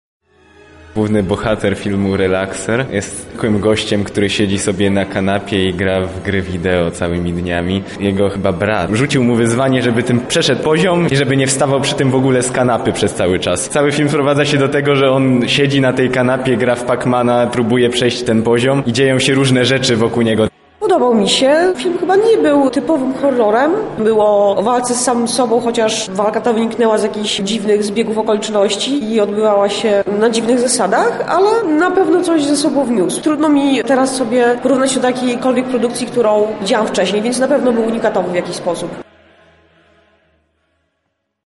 splat relacja